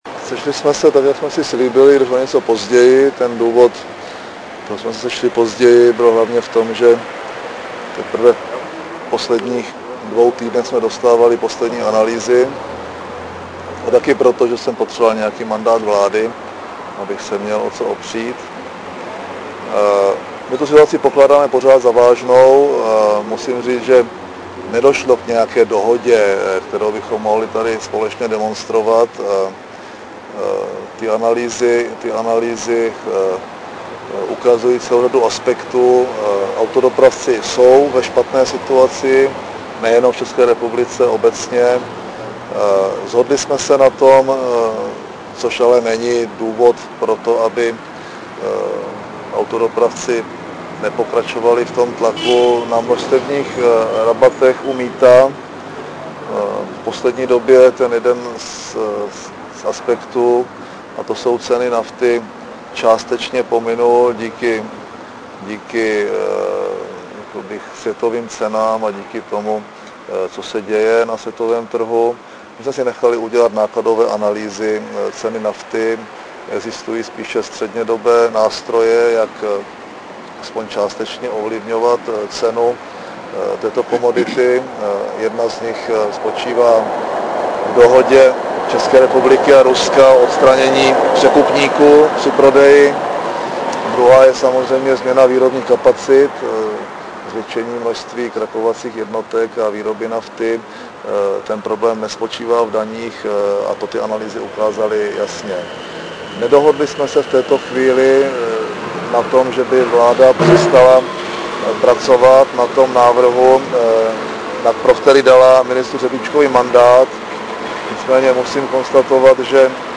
Zvukový záznam komentáře předsedy vlády M. Topolánka